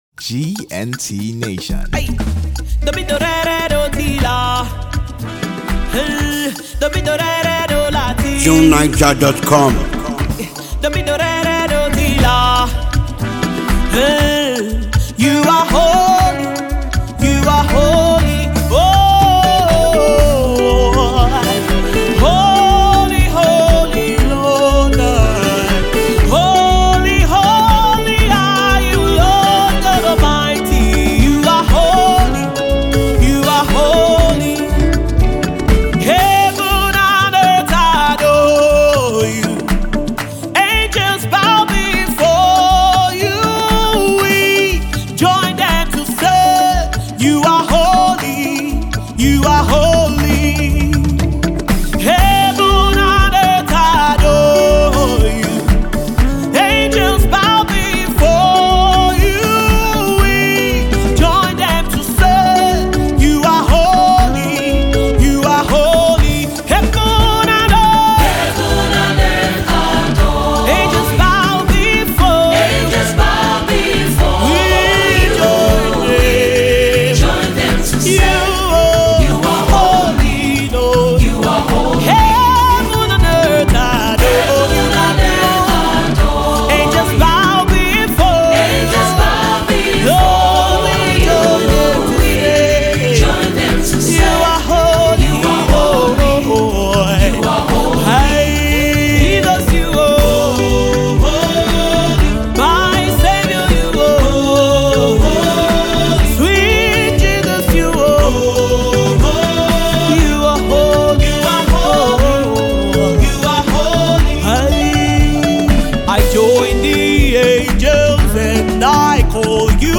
a phenomenally gifted Nigerian gospel singer
Anyone looking for soul-stirring music should have it.